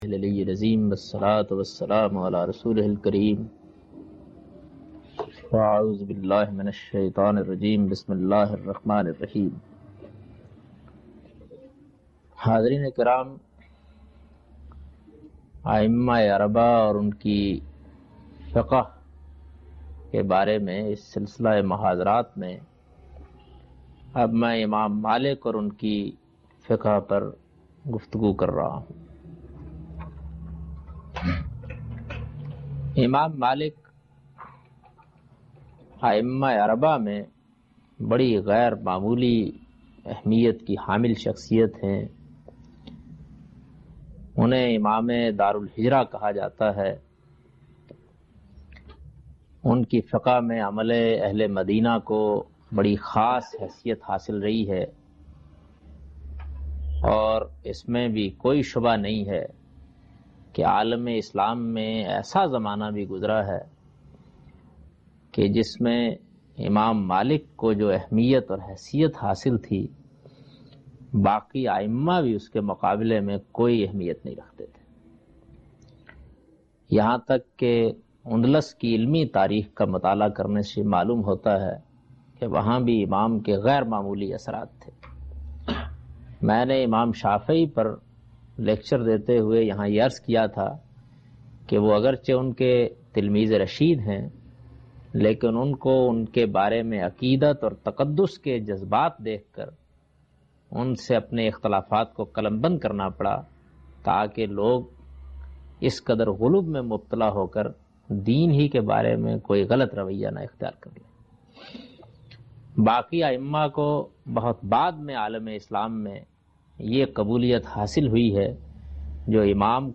In this video Javed Ahmad Ghamidi speaks about Fiqh of Imam Malik.